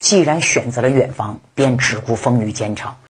Emocjonalny Lektor Audiobooków AI
Przekształć swoje rękopisy w porywające doświadczenia audio dzięki naturalnie brzmiącemu głosowi AI, który doskonale oddaje głębię emocjonalną i niuanse postaci.
Tekst-na-Mowę
Próbka Narracji